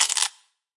来自我的卧室的声音" 硬币添加到硬币 ( 冻结 )
描述：在Ableton中录制并略微修改的声音
声道立体声